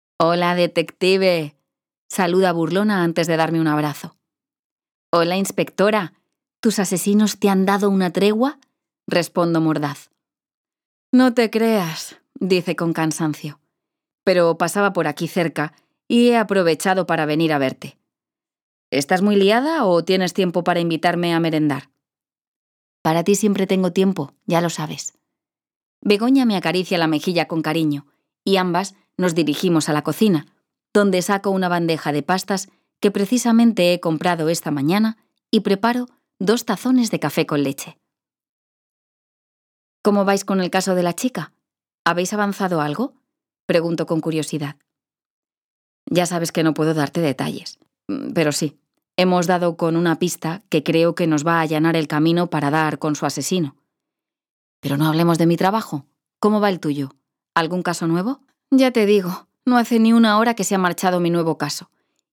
Audiolibro Villa Varea